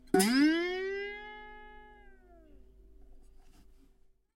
描述：一包我用在办公室找到的旧玩具吉他得到的一些有趣的声音:)希望这对某人有用。 装备：玩具吉他，Behringer B1，便宜的支架，Presonus TubePRE，MAudio Audiophile delta 2496.
Tag: 卡通 字符串 玩具 玩具吉他 吉他